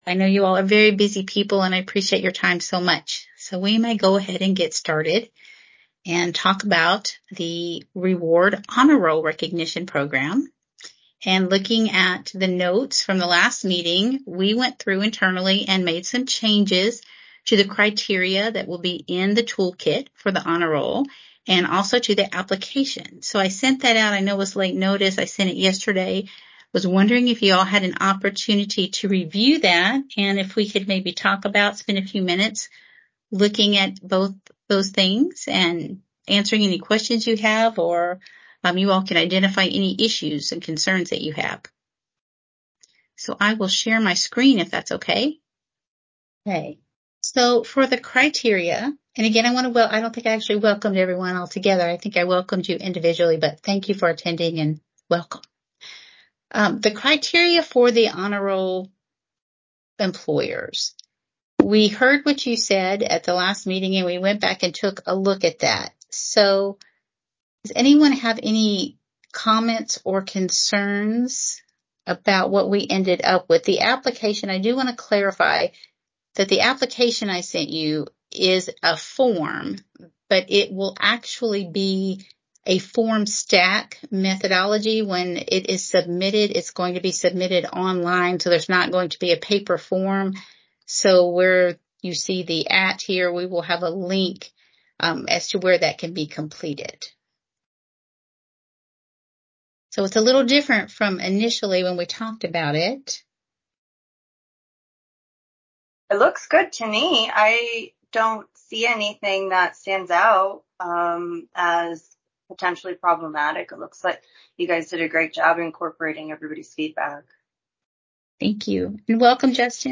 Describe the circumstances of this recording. The meeting will be held virtually via Microsoft Teams.